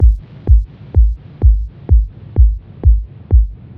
• techno 808 reverb kick.wav
Techno_808_Reverb_Kick_S7E.wav